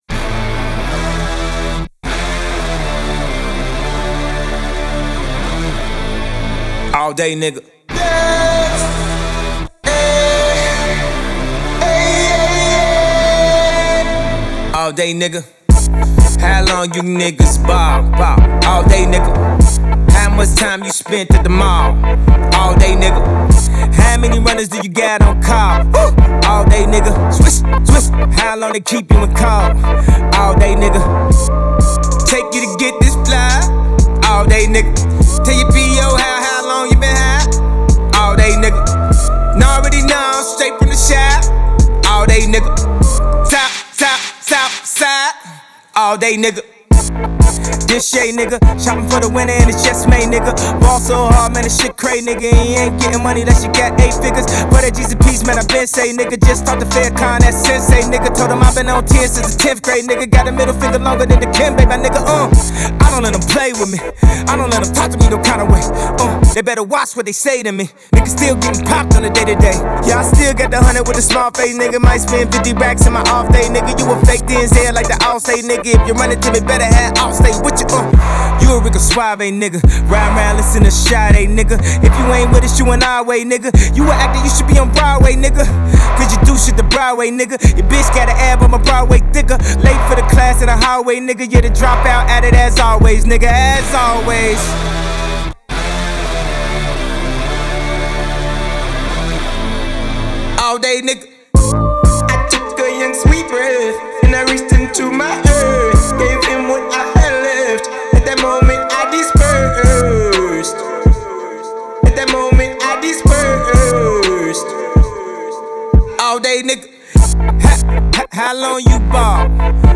brag track